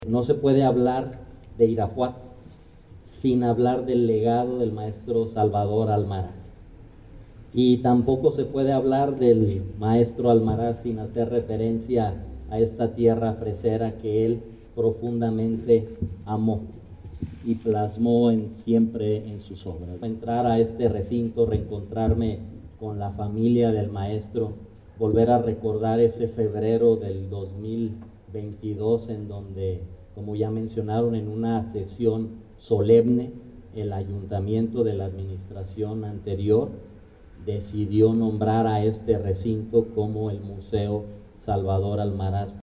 Rodolfo Gómez Cervantes, Secretario del Ayuntamiento